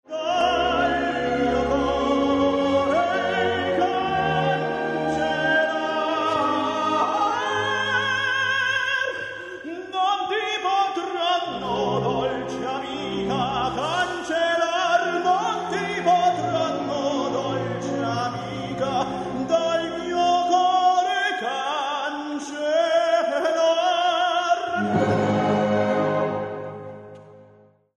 G-f'' (chest voice)
g-c''' (falsetto)
OPERA